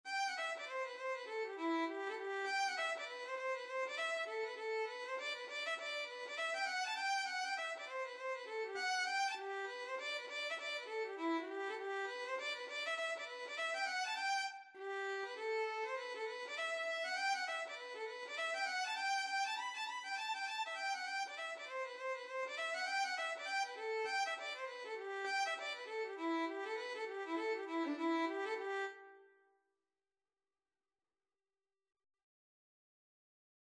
2/4 (View more 2/4 Music)
D5-B6
Violin  (View more Intermediate Violin Music)
Traditional (View more Traditional Violin Music)